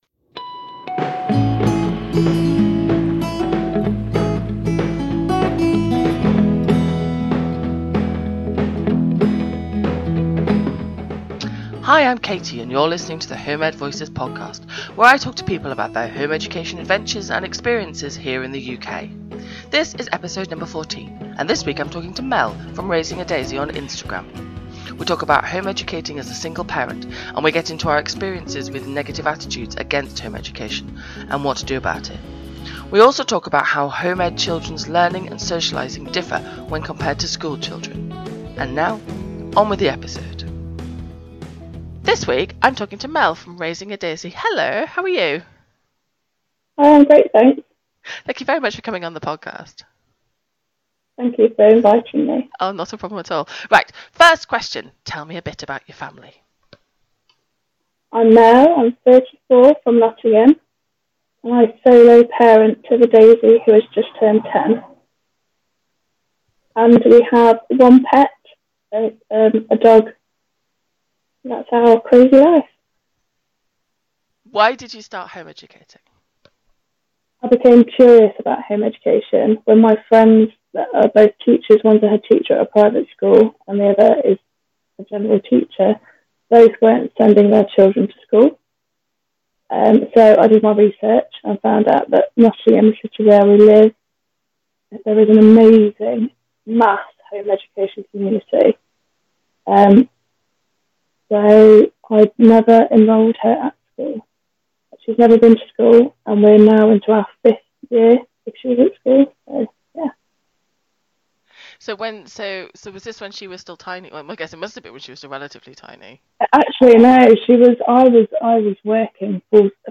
The Home Ed Voices Podcast is a UK-based Home Education Podcast that profiles the lives of home ed families.